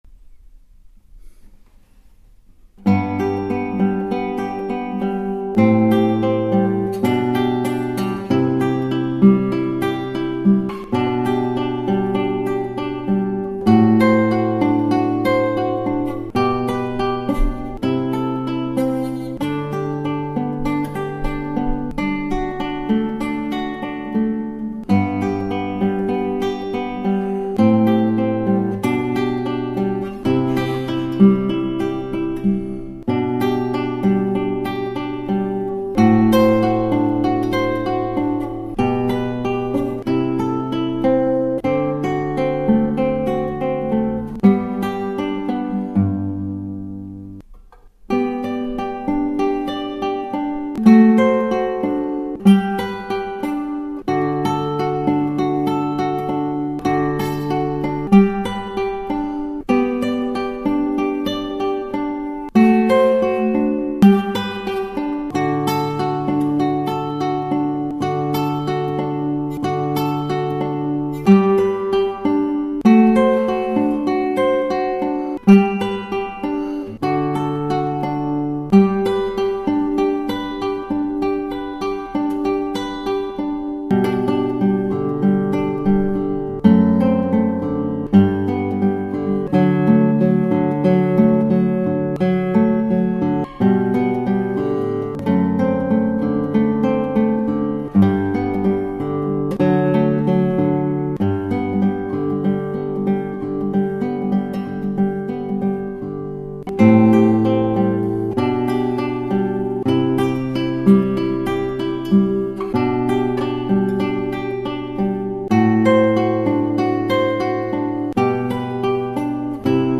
以上ギターはアルカンヘル